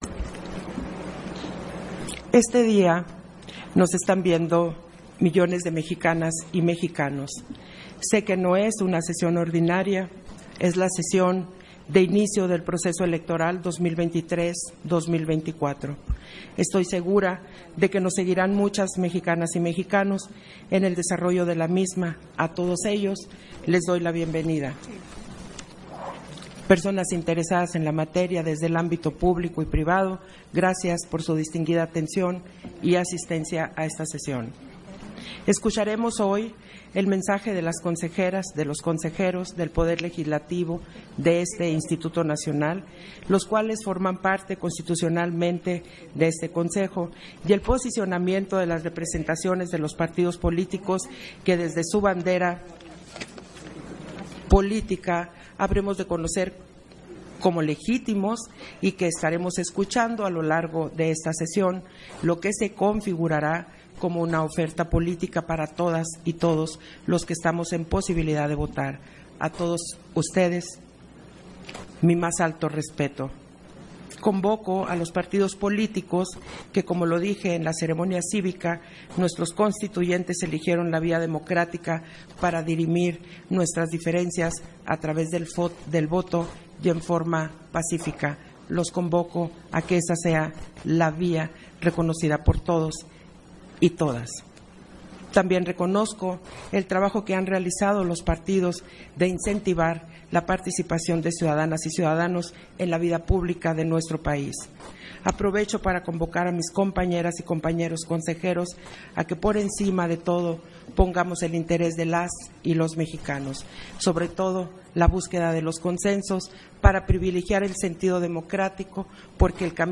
070923_AUDIO_INTERVENCIÓN-CONSEJERA-PDTA.-TADDEI-PUNTO-1-SESIÓN-EXT. - Central Electoral